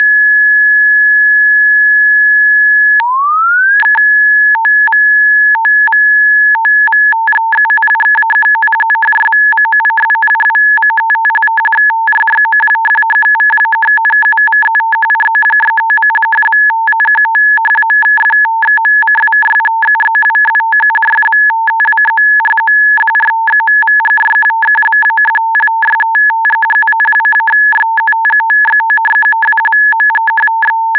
מצו"ב הקובץ המומר של המילה "שלום" 1.wav